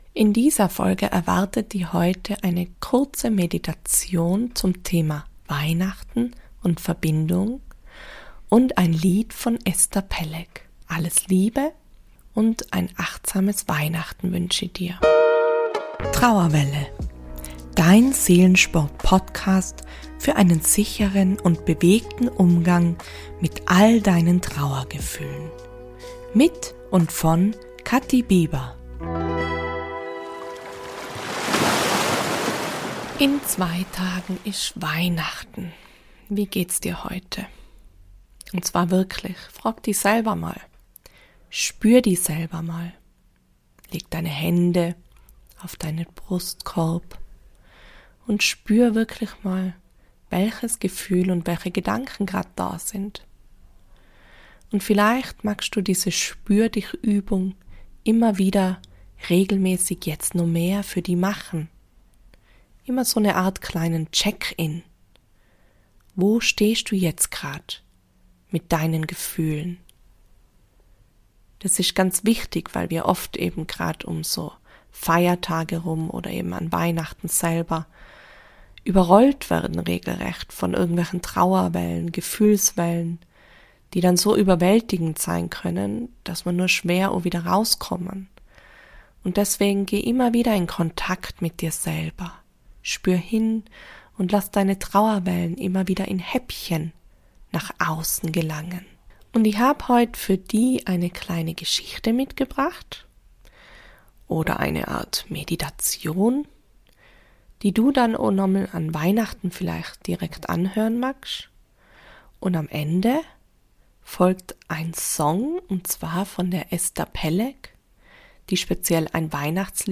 In dieser Folge erwartet dich eine kurze Meditation zum Thema Verbindung und Weihnachten und ein paar Worte an dich und deine Trauer.